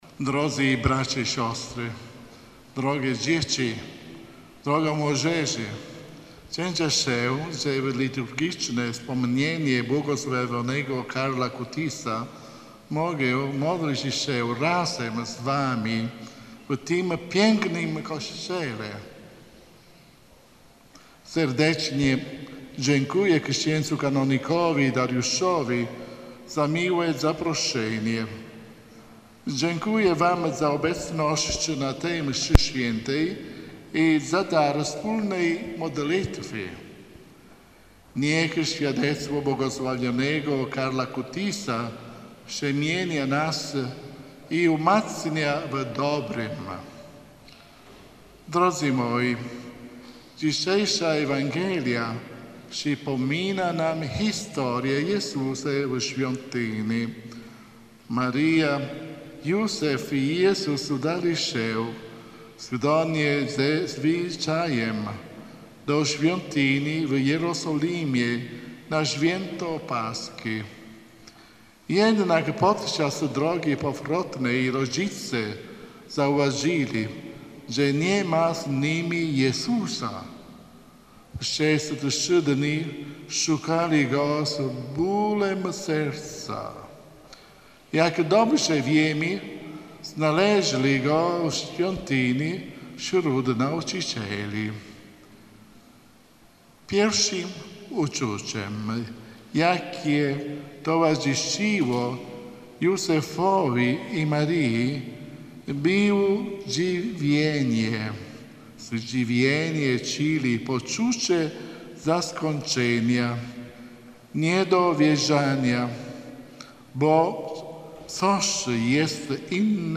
Nuncjusz Apostolski w Polsce przewodniczył Mszy Świętej w parafii św. Hieronima w Starej Miłośnie.
Abp Salvatore Pennacchio podczas homilii podkreślił, że błogosławiony Carlo powinien być autorytetem dla wszystkich młodych. Nuncjusz Apostolski zaznaczył także, że w życiu błogosławionego to właśnie Jezus był najważniejszy.